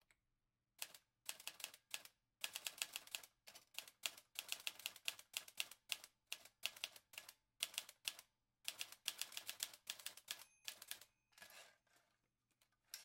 typewriter.m4a